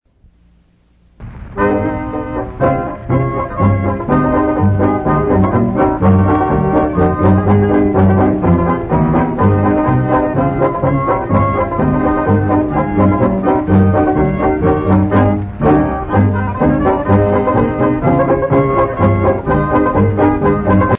Cleveland Style (Slovenian)